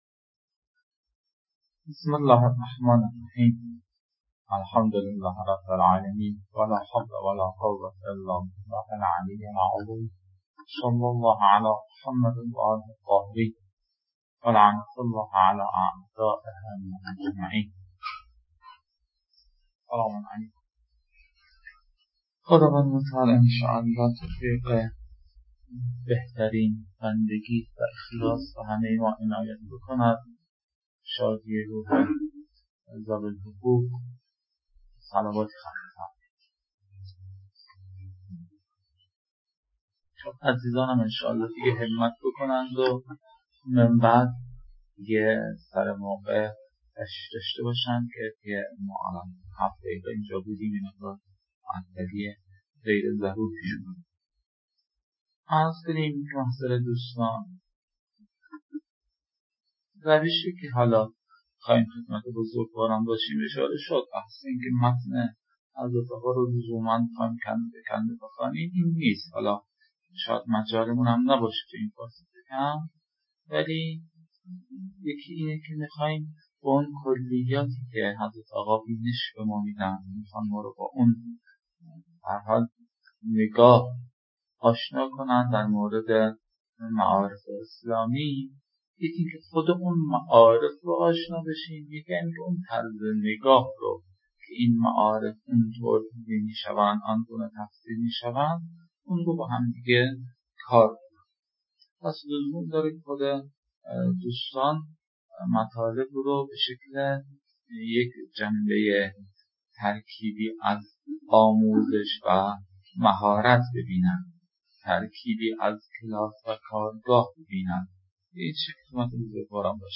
🔸 لازم به‌ذکر است که نتیجه این رویکرد، گرفته شدن وقت قابل توجهی در محتوای صوت‌ها به رفت‌وبرگشت بین استاد و طلاب است که در کنار مجازی برگزارشدن کلاس، حوصله خاصی را در گوش دادن می‌طلبد.